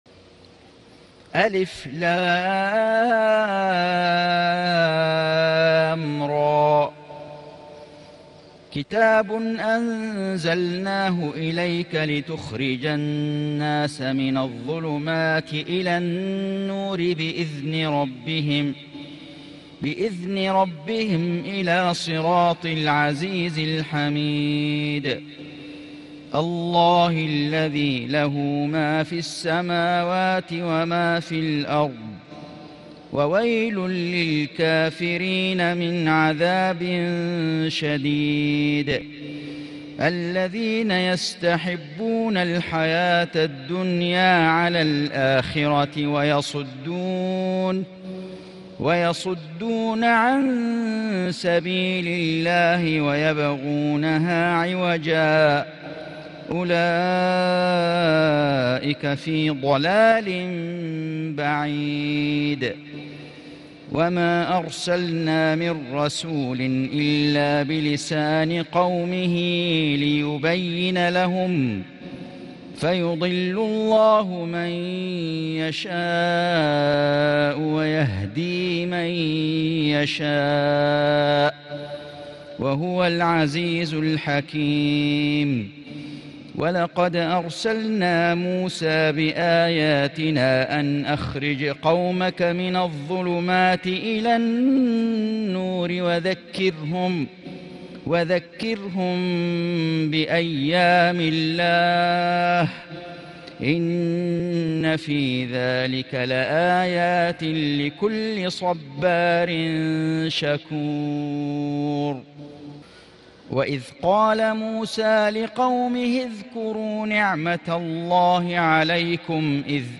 سورة إبراهيم > السور المكتملة للشيخ فيصل غزاوي من الحرم المكي 🕋 > السور المكتملة 🕋 > المزيد - تلاوات الحرمين